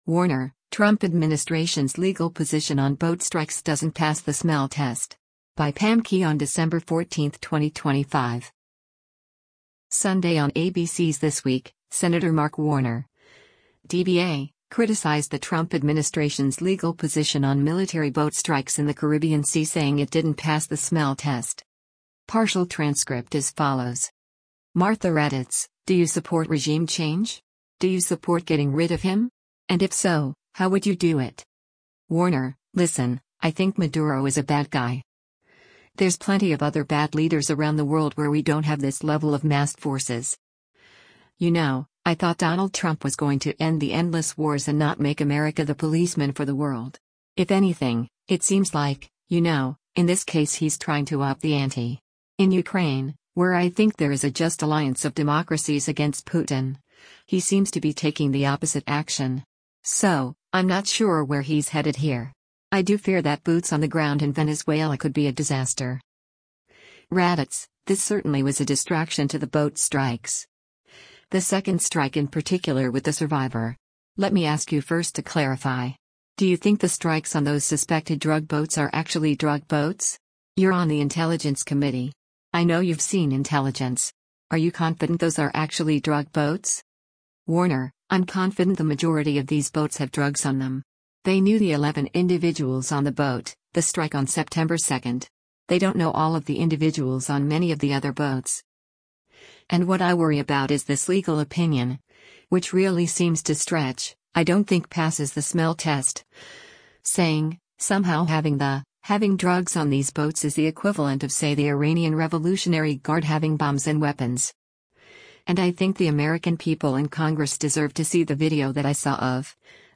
Sunday on ABC’s “This Week,” Sen. Mark Warner (D-VA) criticized the Trump administration’s legal position on military boat strikes in the Caribbean Sea saying it didn’t pass the “smell test.”